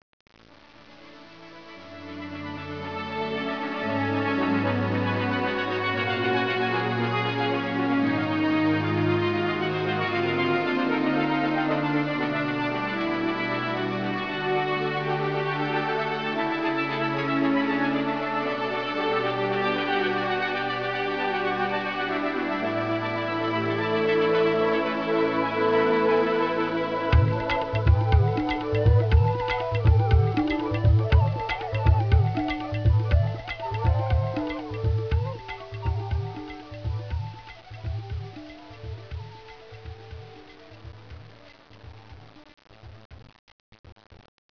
Ceris is not a song, but an interlude.